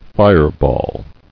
[fire·ball]